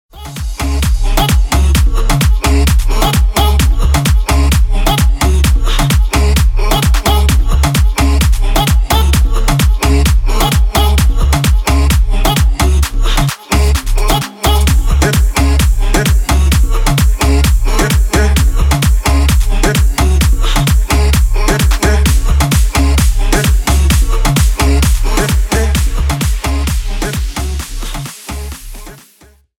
Электроника # без слов # клубные